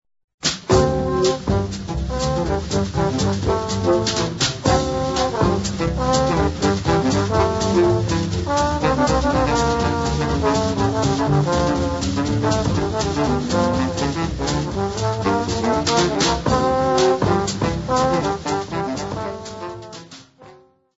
Área:  Jazz / Blues